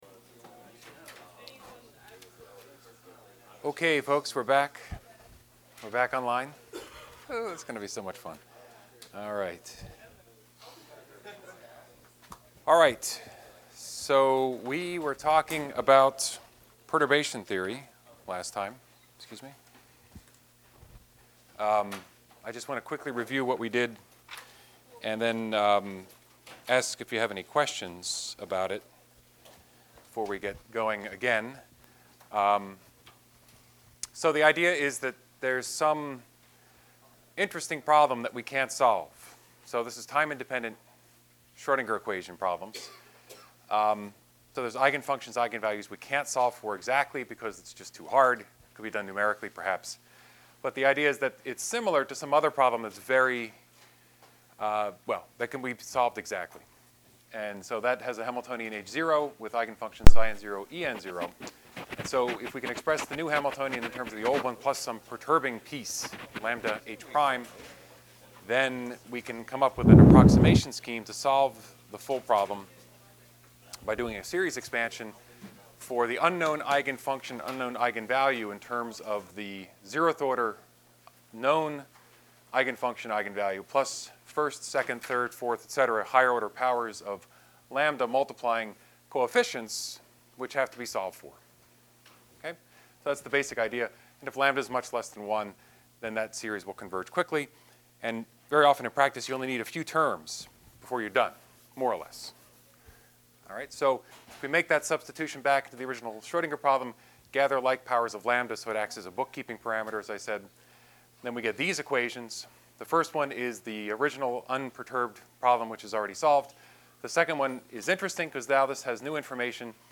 Lecture 10 Audio Recording